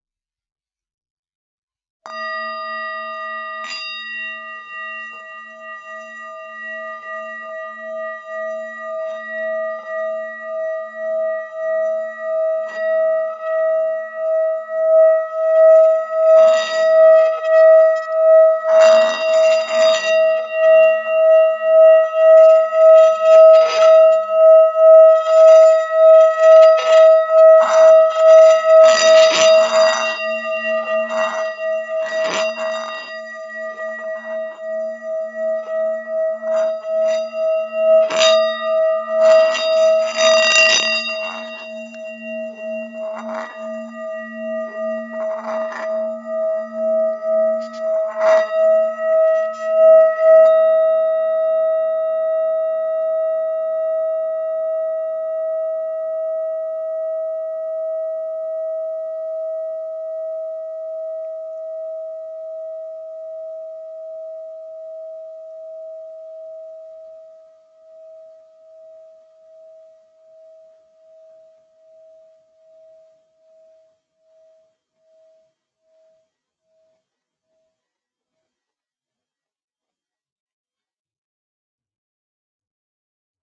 歌唱碗" klankschaal 1 hamer1 laag
描述：录制唱歌碗
标签： 松弛 唱歌
声道立体声